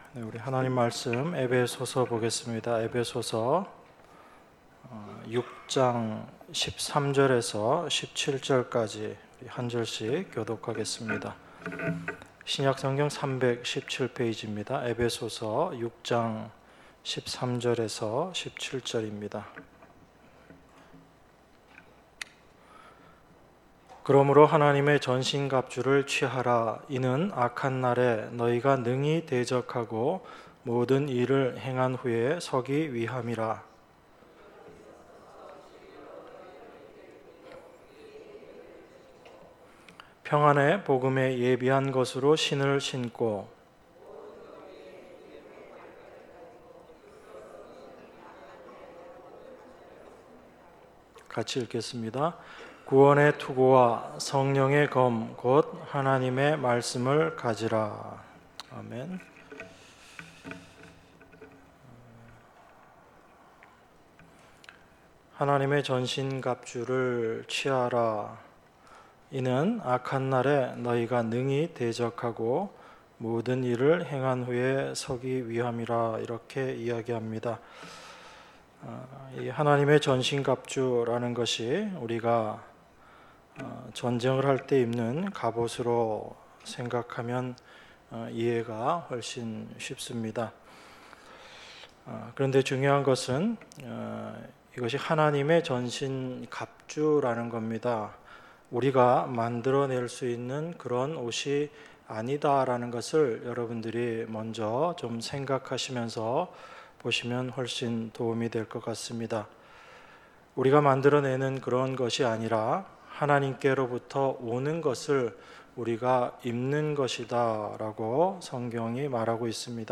주일예배 - 에베소서 6장 13절~17절 주일2부